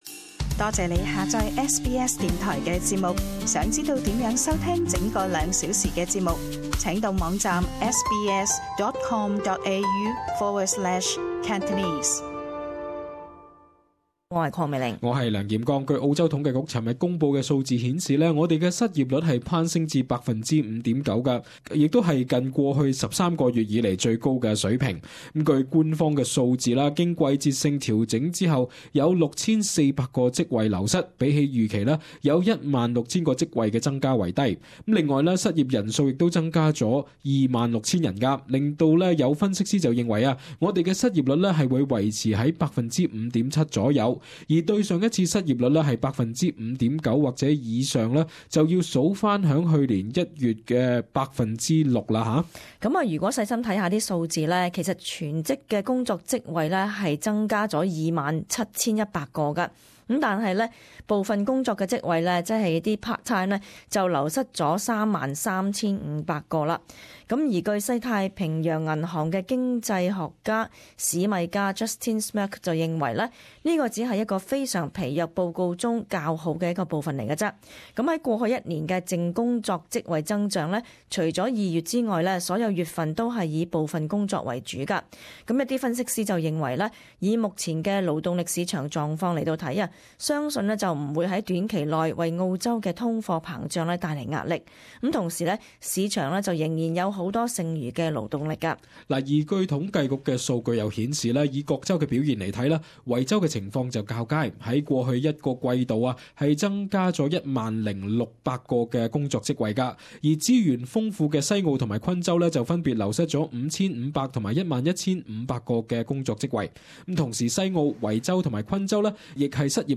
【時事報導】工黨召開工作峰會對應失業高企